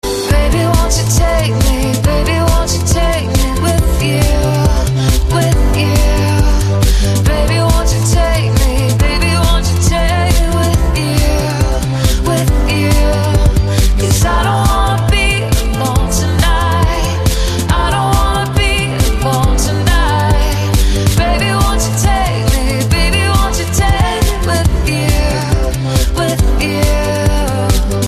M4R铃声, MP3铃声, 欧美歌曲 42 首发日期：2018-05-13 09:56 星期日